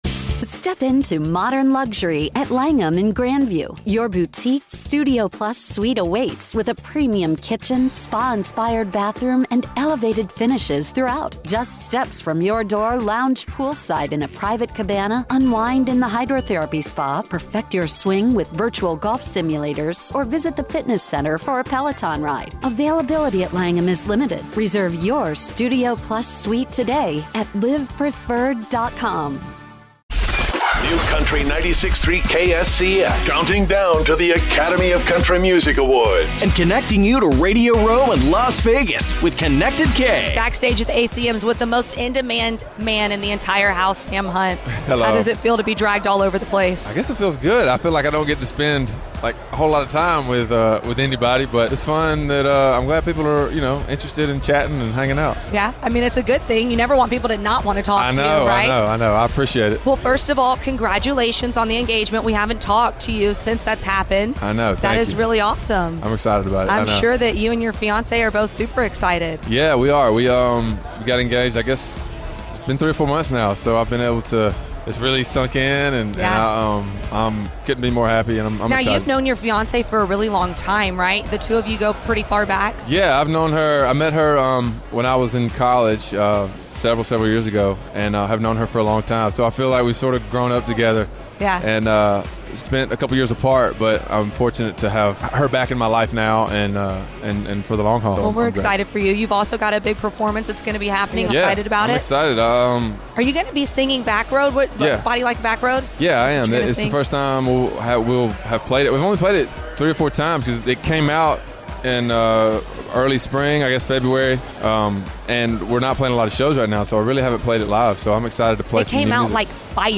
ACM Interview Sam Hunt